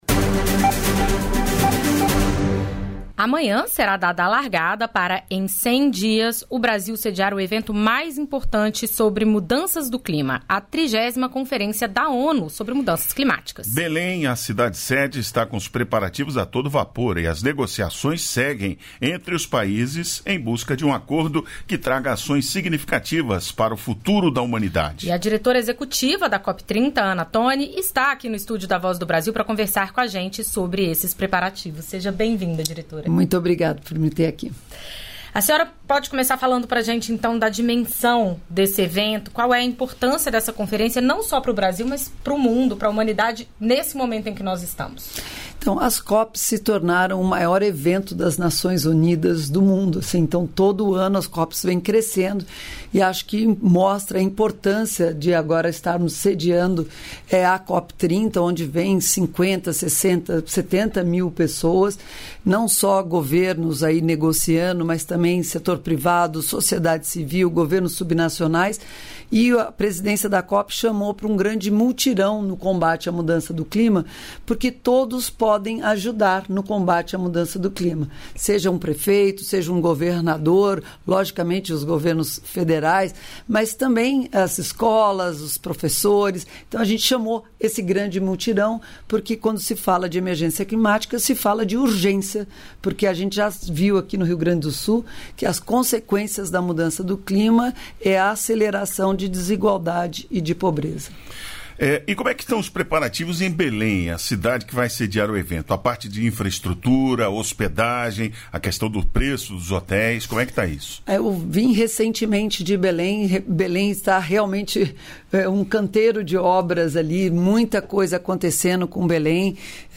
Entrevistas da Voz